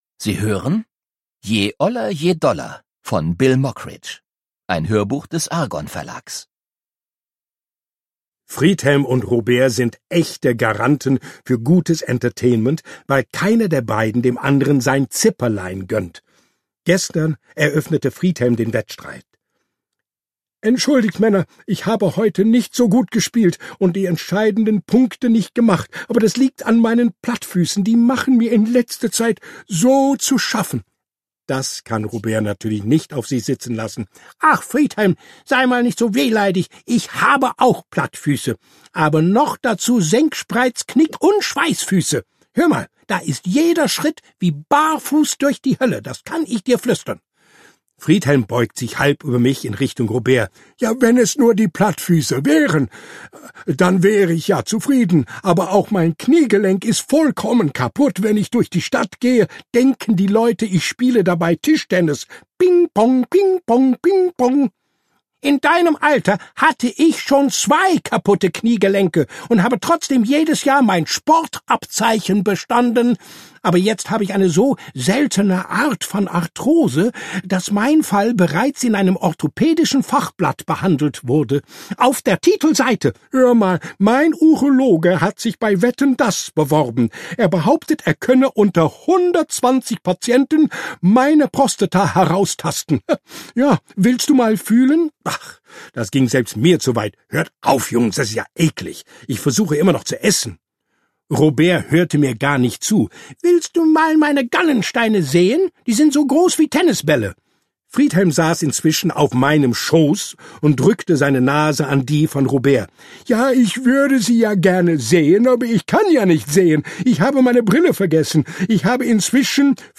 Bill Mockridge (Sprecher)